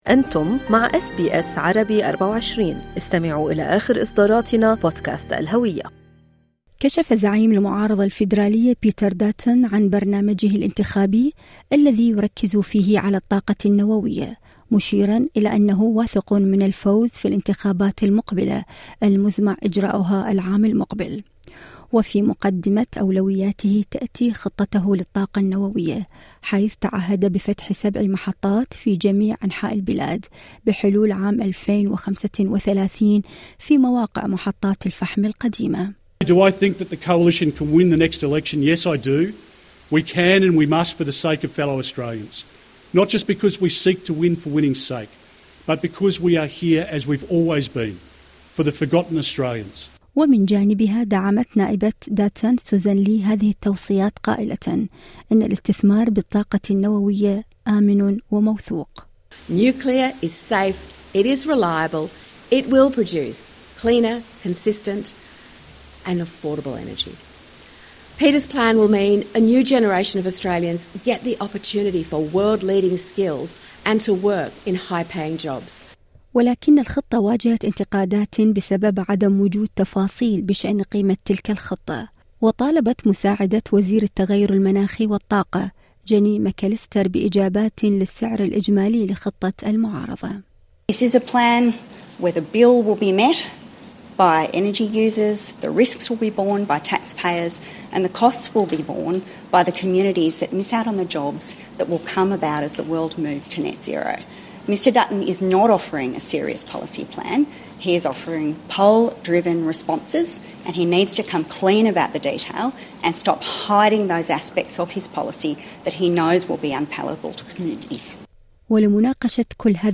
الجواب في المقابلة الصوتية اعلاه